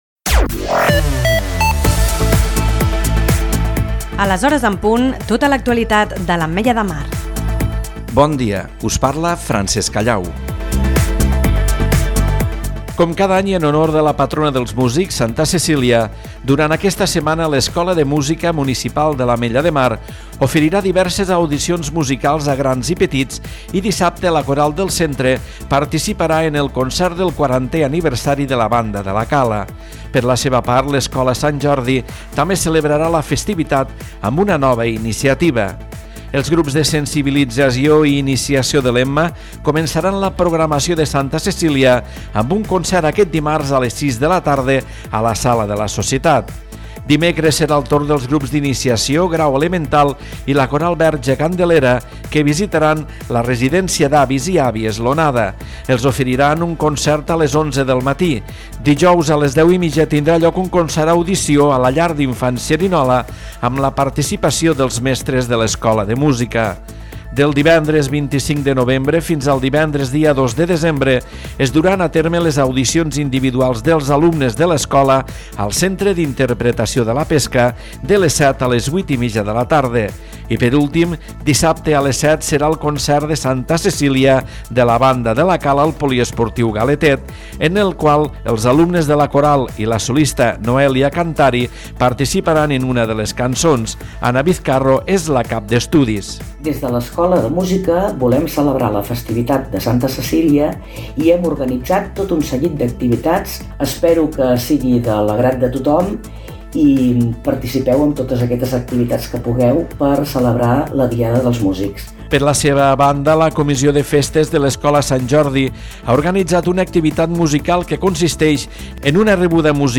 Butlletí Informatiu 22/11/2022